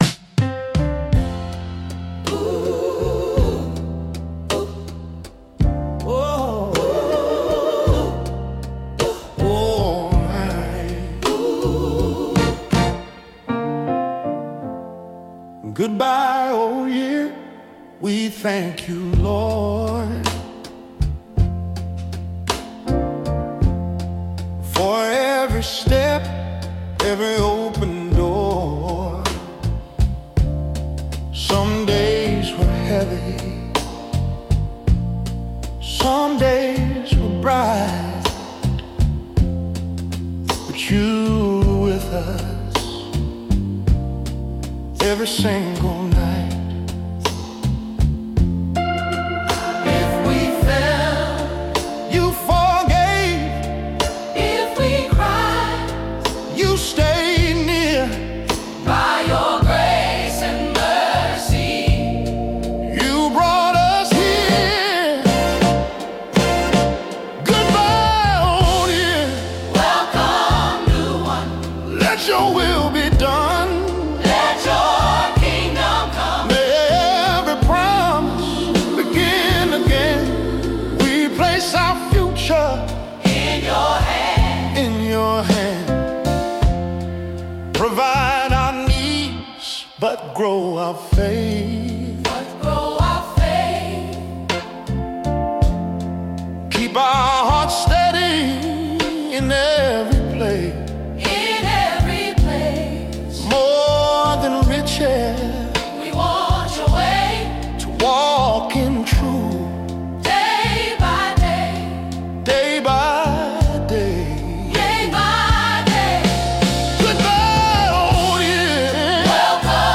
Goodbye Old Year, Welcome New Year - Soulful Black Gospel